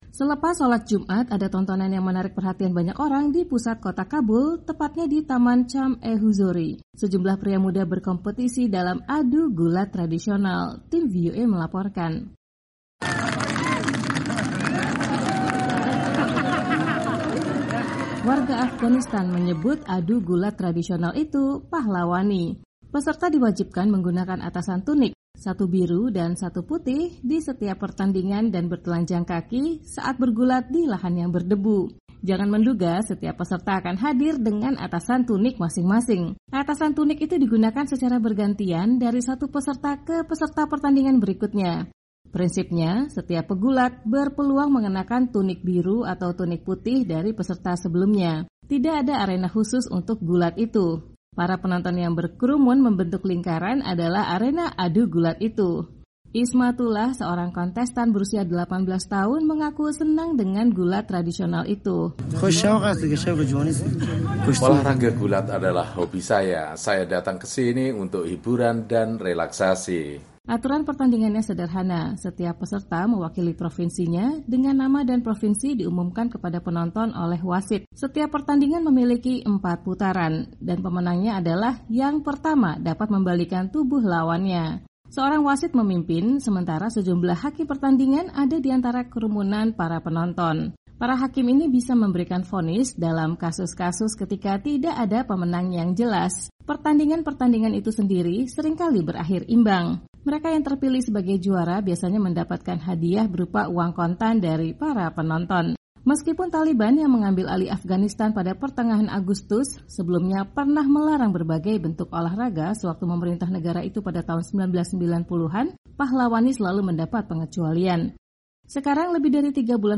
Sejumlah pria muda berkompetisi dalam adu gulat tradisional. Tim VOA melaporkan.